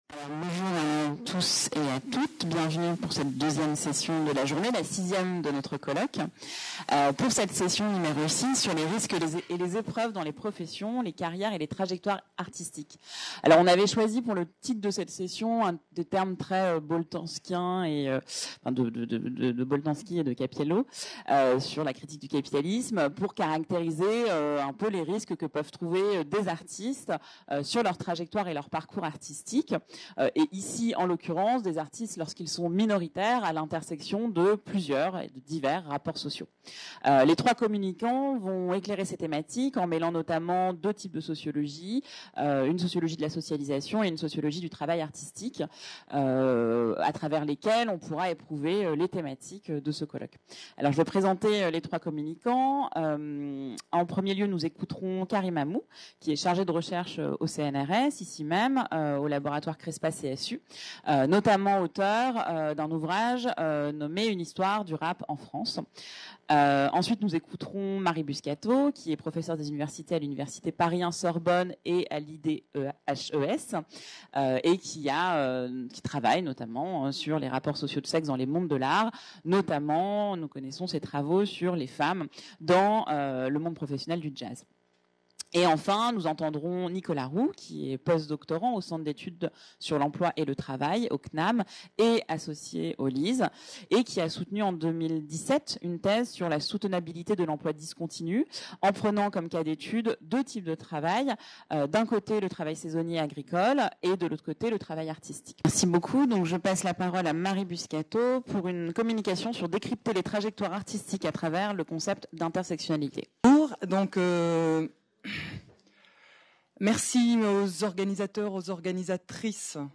3ème colloque du RT14 et RT24 de L'AFS Vidéo 8 session 6 | Canal U